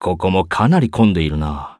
文件 文件历史 文件用途 全域文件用途 Ja_Bhan_tk_04.ogg （Ogg Vorbis声音文件，长度1.8秒，115 kbps，文件大小：25 KB） 源地址:游戏语音 文件历史 点击某个日期/时间查看对应时刻的文件。 日期/时间 缩略图 大小 用户 备注 当前 2018年5月25日 (五) 03:00 1.8秒 （25 KB） 地下城与勇士  （ 留言 | 贡献 ） 分类:巴恩·巴休特 分类:地下城与勇士 源地址:游戏语音 您不可以覆盖此文件。